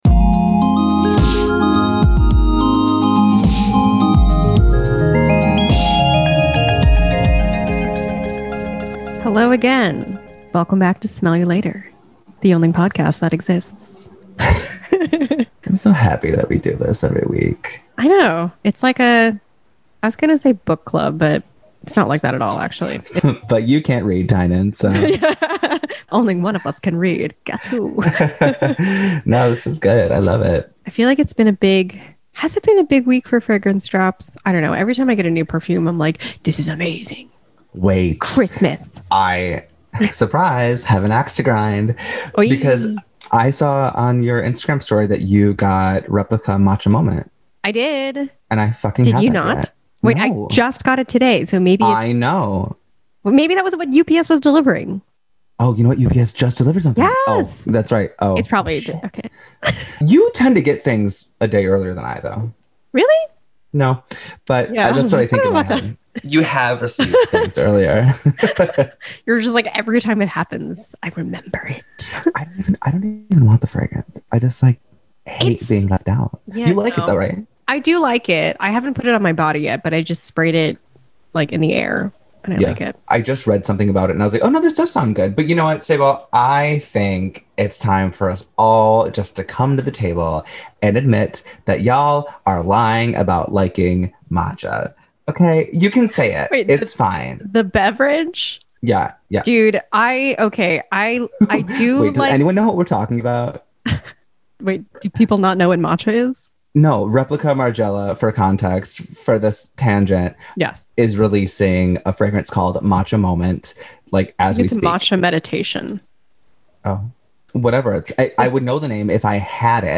Here's a long-form rant about the usage of the term "oriental" in fragrance.
You may have noticed how the co-hosts of this podcast audibly cringe every time we talk about a fragrance that is marketed as an "oriental" scent.